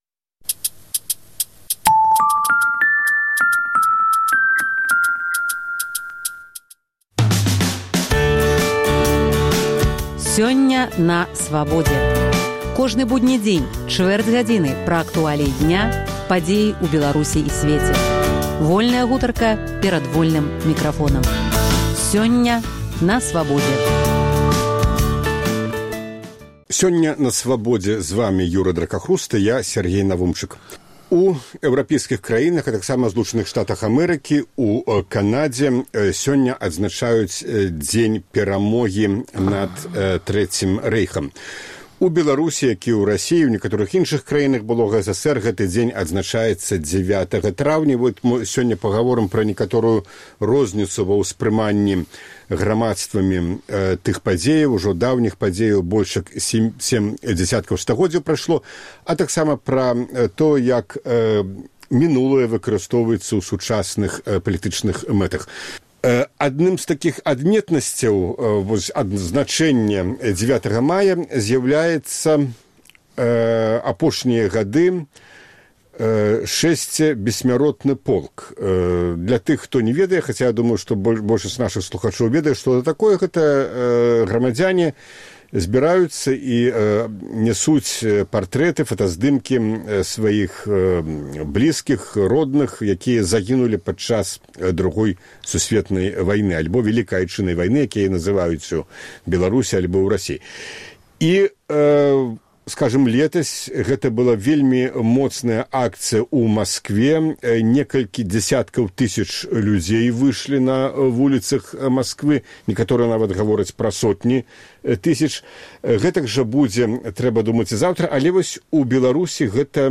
Абмяркоўваюць